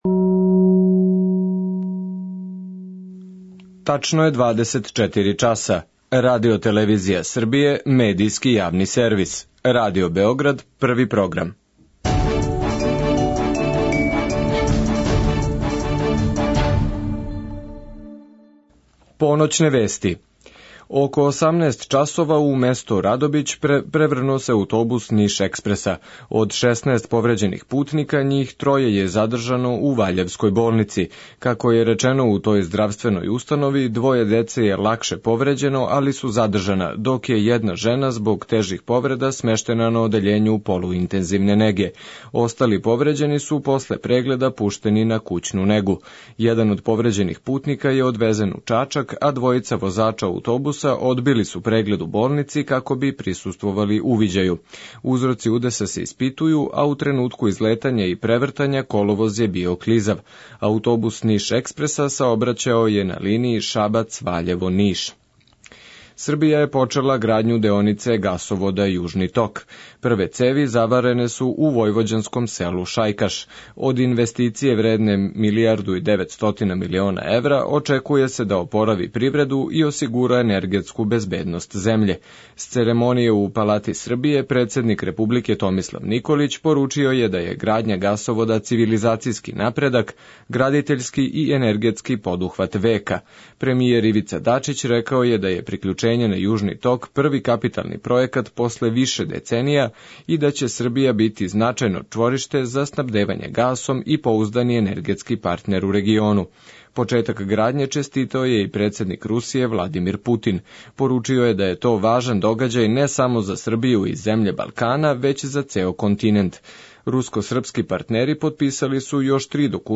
У Сновреме стижу млади уметници, чланови групе Гете-Герила при Гете-Институту у Београду. Доносе нам приче о једном посебном делу града, о сликама, звуцима, различитим радионицама и пројектима.